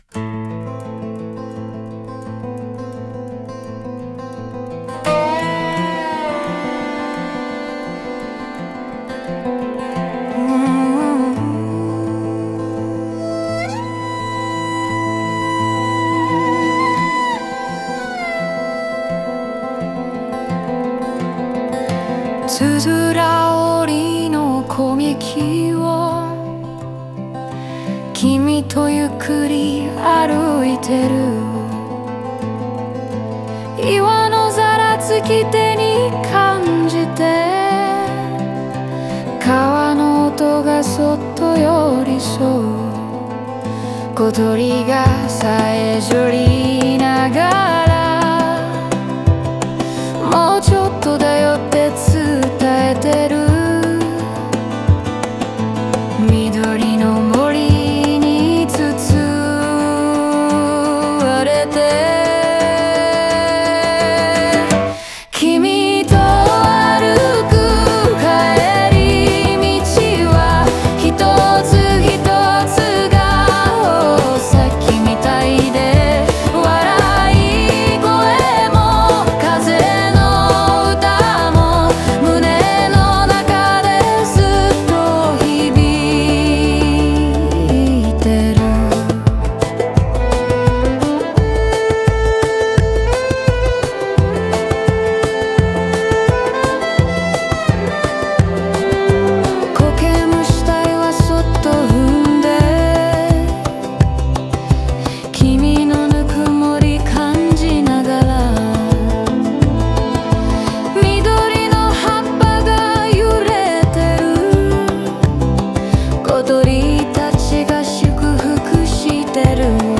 語りかけるような柔らかい女性の歌声が、自然の息づかいと共鳴し、互いの温もりや安心感を伝えます。